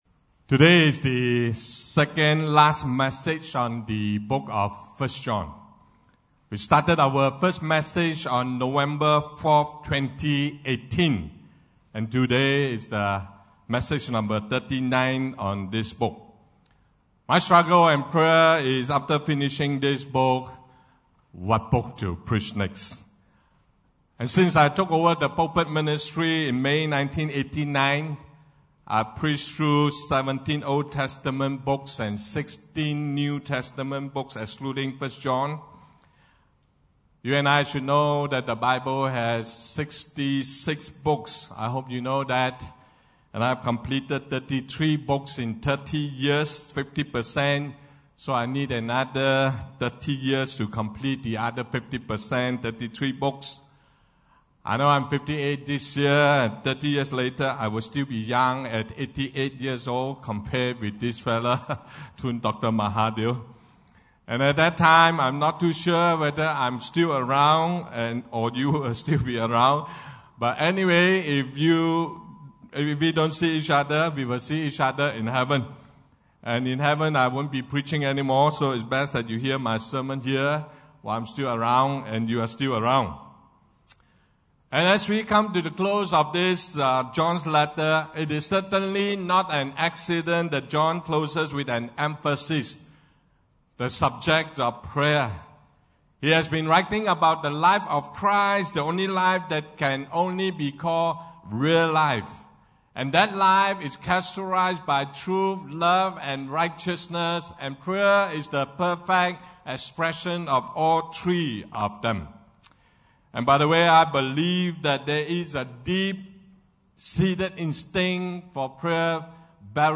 Service Type: Sunday Service (English)